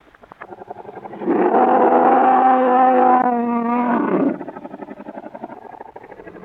Monster Roar Reversed